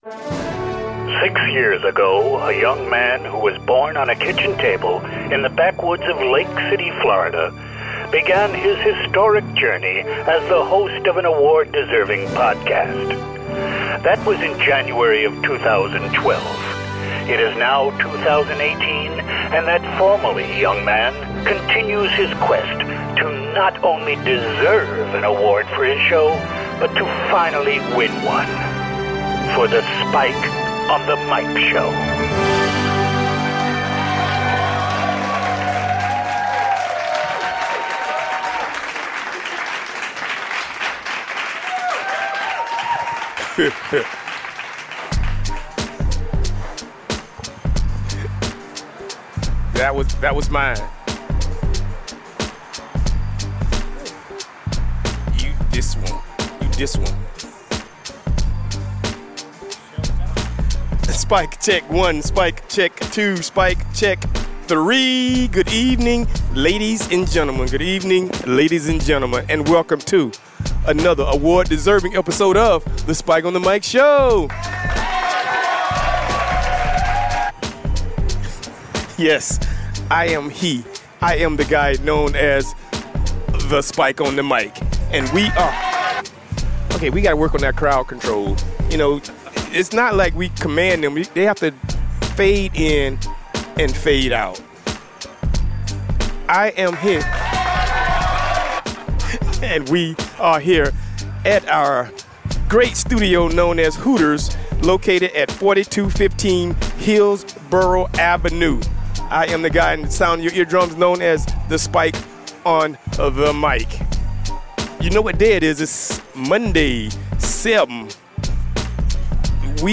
Live from Hooters in Tampa.